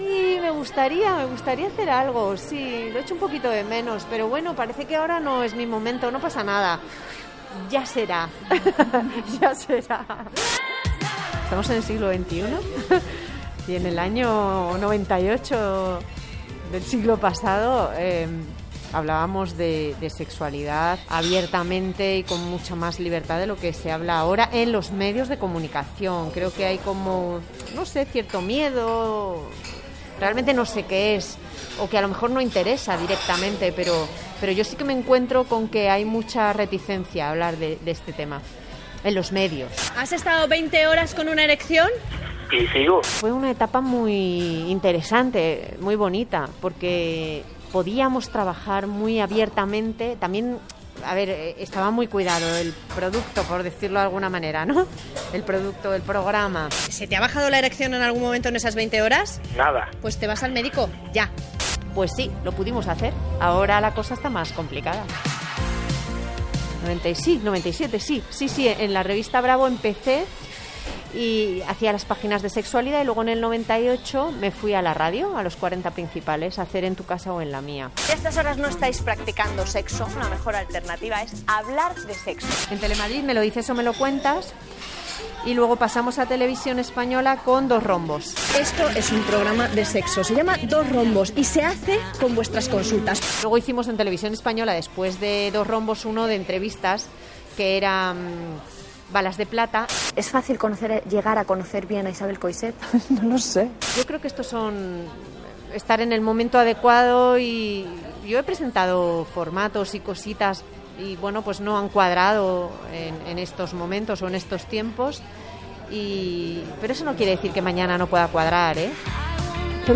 La psicóloga, sexòloga i presentadora de ràdio i televisió Lorena Berdún recorda els programes sobre sexe que havia presentat ( 'Me lo dices o me lo cuentas' a Cadena 40 Principales o 'Dos rombos', a TVE, entre altres)
Entreteniment